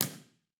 Loudspeaker: Isobel Baritone Half-dodec
Microphone: Sennheiser Ambeo
Source: 14 sec log sweep
Playback RIR:
Test Position 1 – 3 m -XYStereo
RIR_TP1_Isobel_Ambio_3m_B4_XYSTEREO.wav